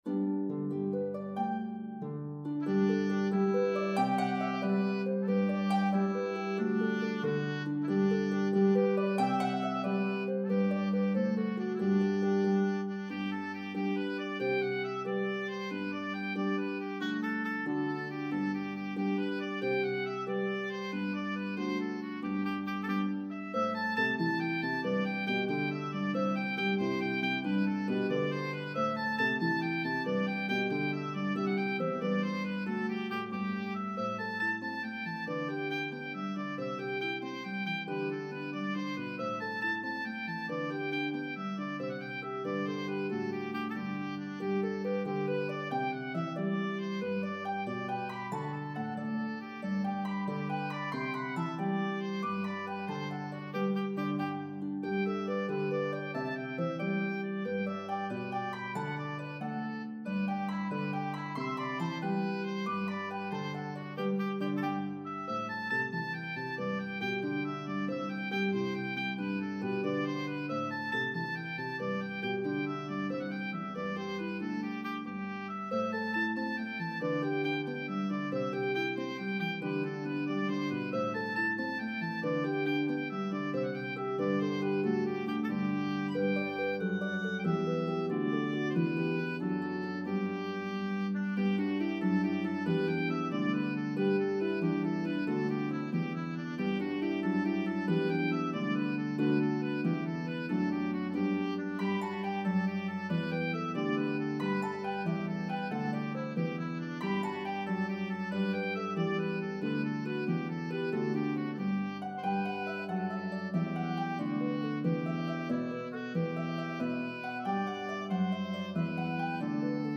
a medley of two joyful, upbeat Irish Jigs